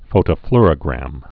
(fōtə-flrə-grăm, -flôr-)